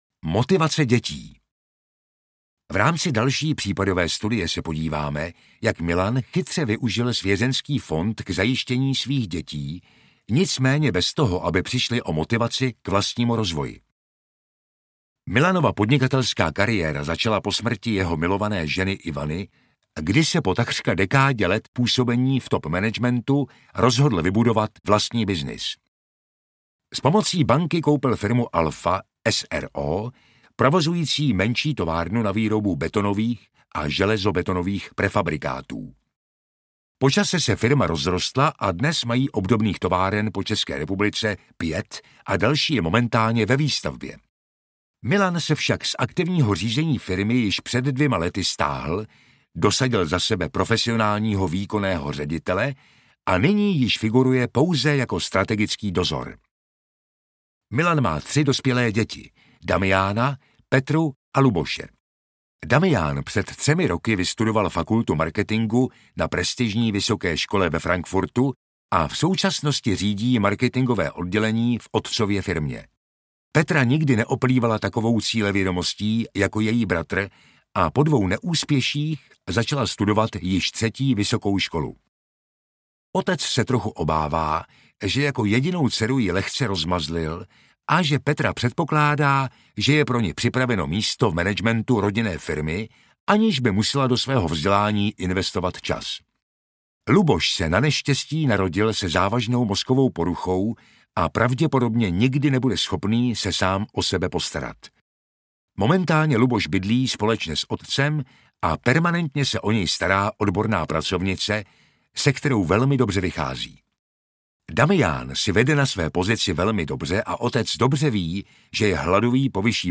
Svěřenský fond - návod k použití aneb Svěřenský fond očima účetního, auditora, znalce, daňového, transakčního a právního poradce audiokniha
Ukázka z knihy